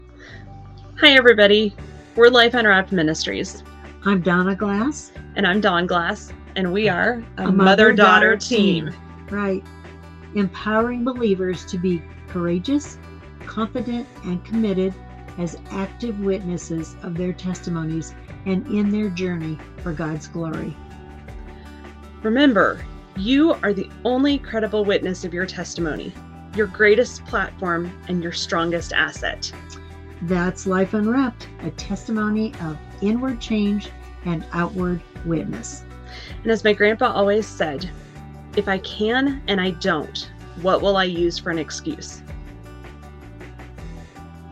lu-intro-march-2022-with-music.mp3